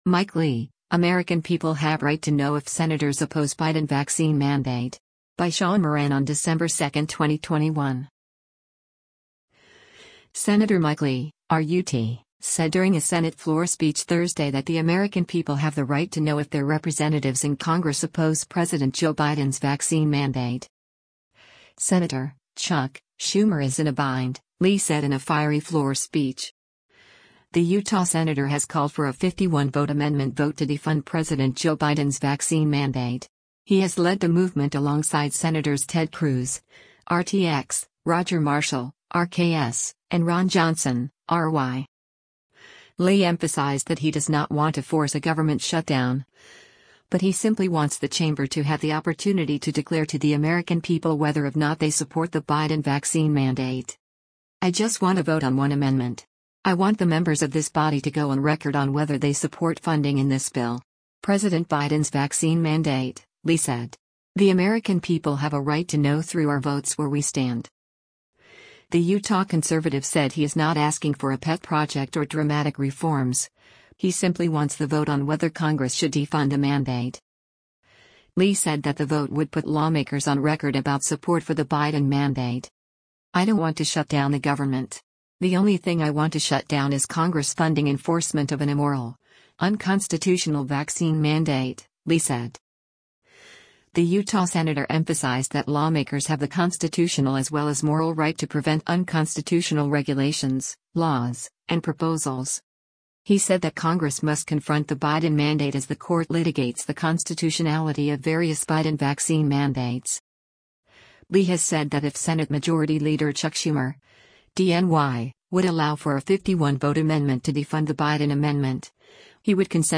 Sen. Mike Lee (R-UT) said during a Senate floor speech Thursday that the American people have the right to know if their representatives in Congress oppose President Joe Biden’s vaccine mandate.